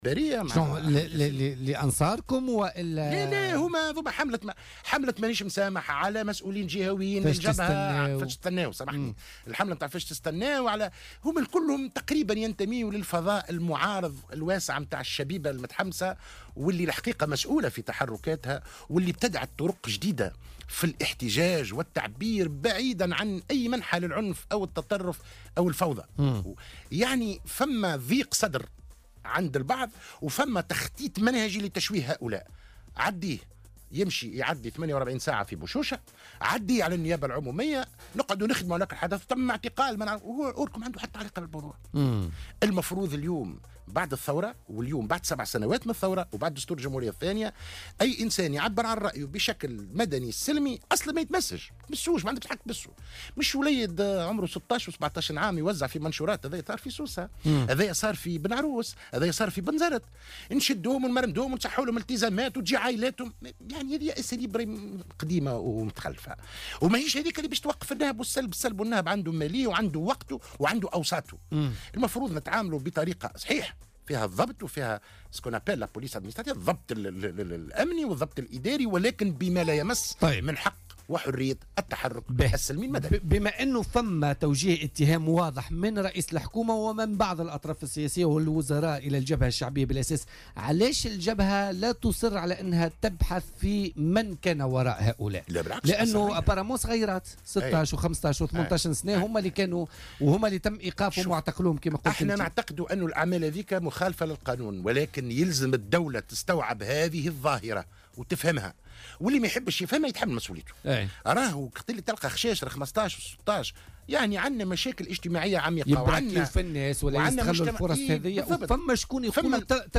ودعا ضيف "بوليتيكا" على "الجوهرة اف أم" اليوم الاثنين، الدولة لاستيعاب هذه الظاهرة وفهمها خاصة وأن أطفالا كانوا ضمن هذه التحركات التخريبية.